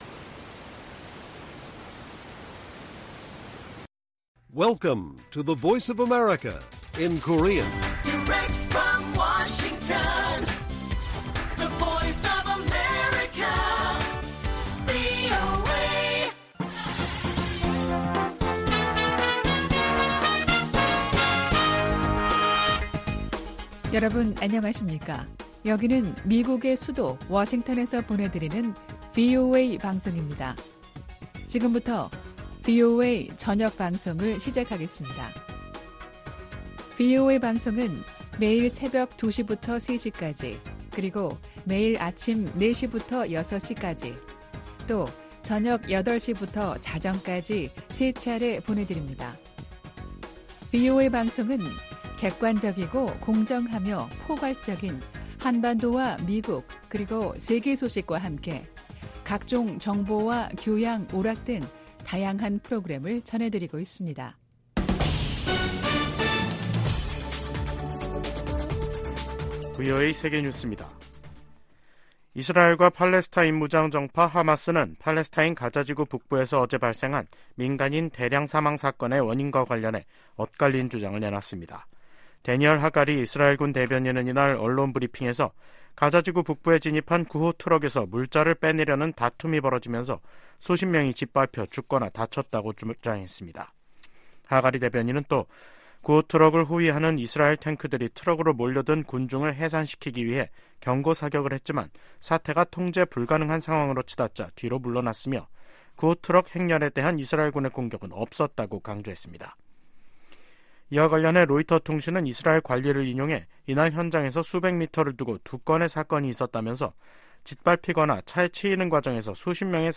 VOA 한국어 간판 뉴스 프로그램 '뉴스 투데이', 2024년 3월 1일 1부 방송입니다. 북한이 미국의 우주 체계를 위협할 사이버전과 전자전 역량을 갖췄다고 미군 고위 당국자들이 평가했습니다. 윤석열 한국 대통령은 제105주년 3.1절 기념식에서 북한에 자유와 인권을 확장하는 게 통일이라고 말했습니다. 커트 캠벨 미 국무부 부장관이 방미 중인 조태열 한국 외교부 장관을 만나 북한 문제와 북-러시아 무기거래, 미한동맹 강화 등을 논의했습니다.